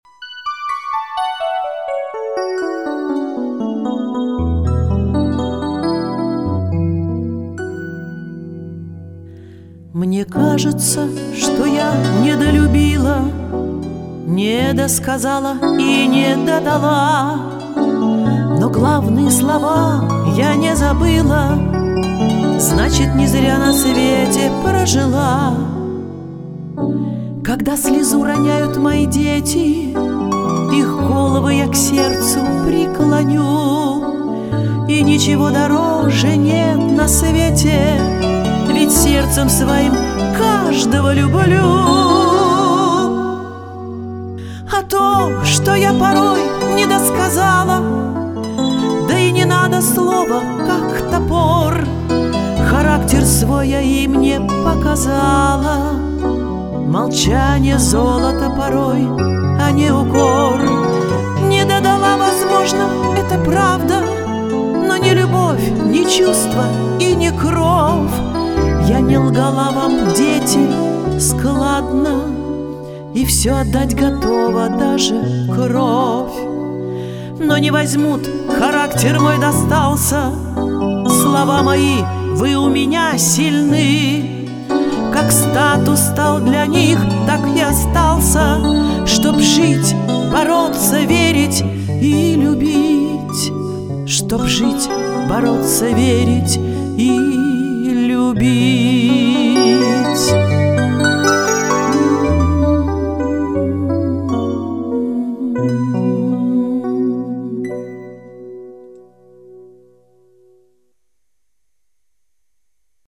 Приятные голоса!